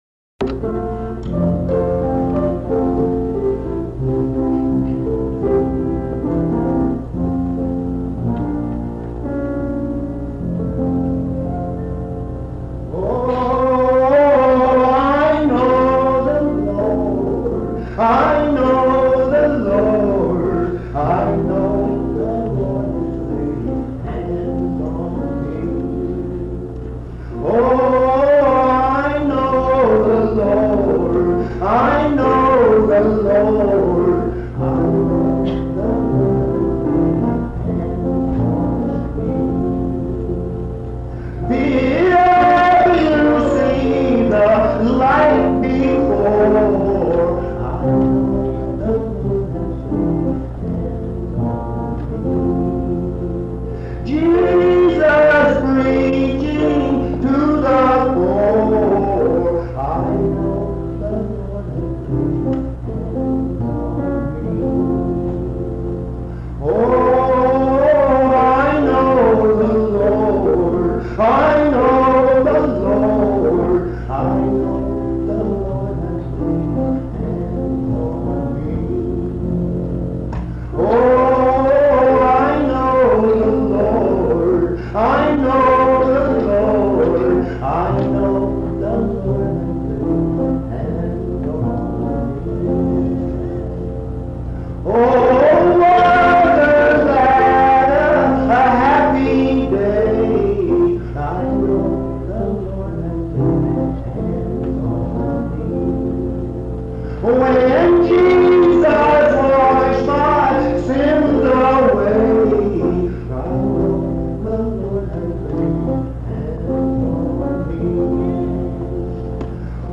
This recording is from the Monongalia Tri-District Sing. Highland Park Methodist Church, Morgantown, Monongalia County, WV.